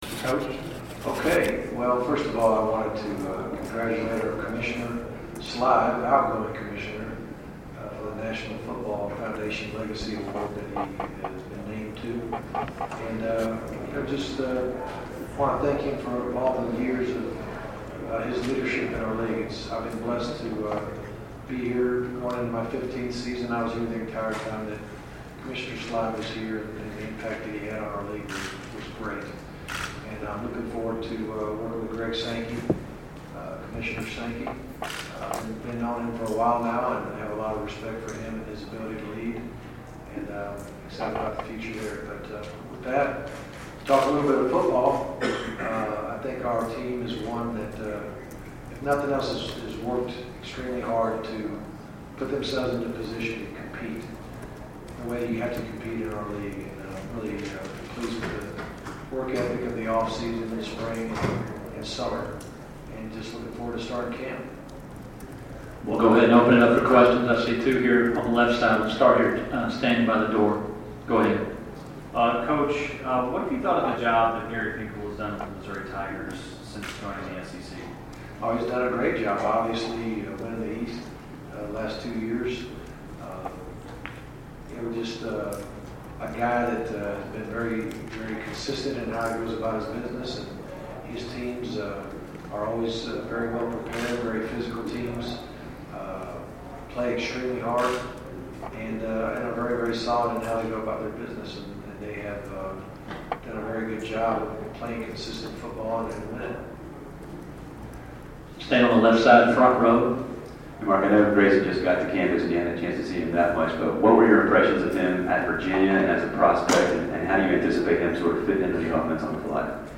Georgia head coach at SEC Media Days 2015